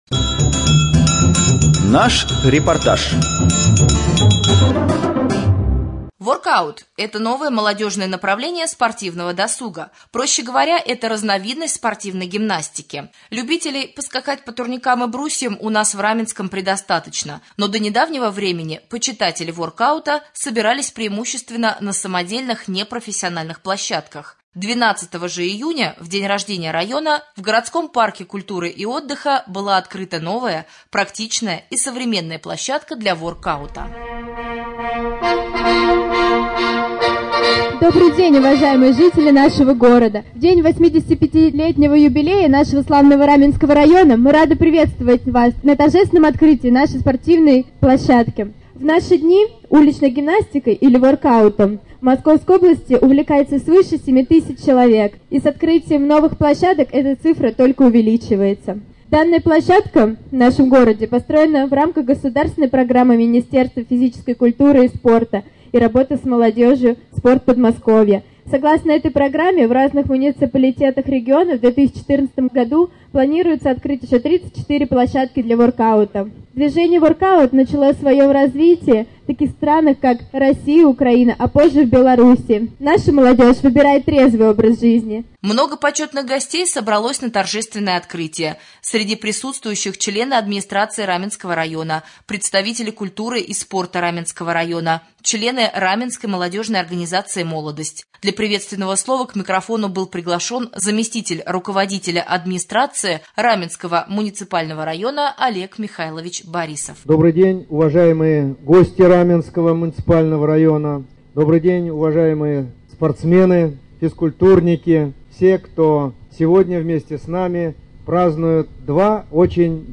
1.Рубрика «Специальный репортаж». В городском парке открылась спортивная площадка для воркаута.